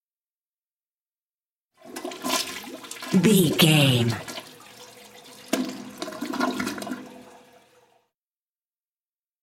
Bathroom flush lavatort
Sound Effects
No